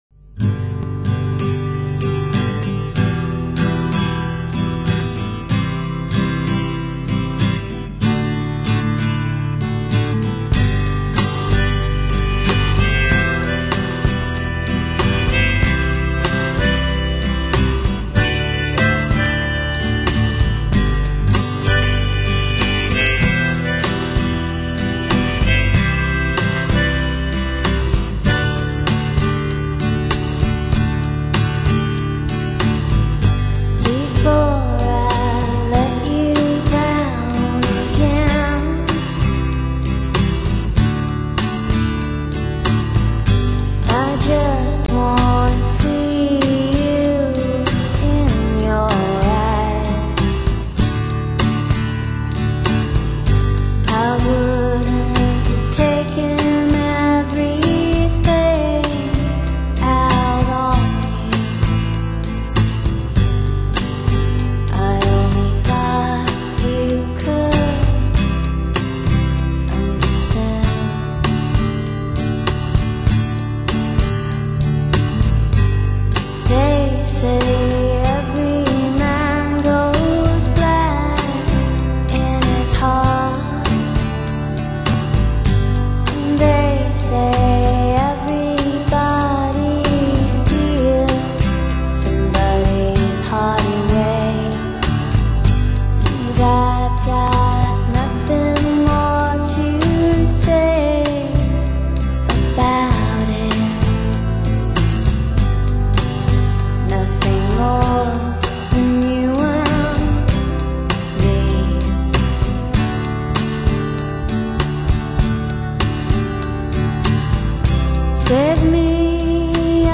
the incredible vocals
other-worldly of the intoxicating kind.